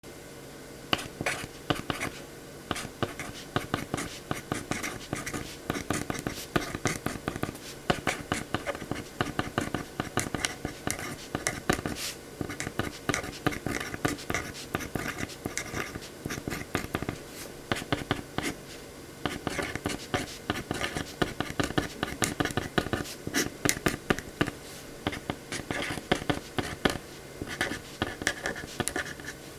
書く　ボールペン カリカリ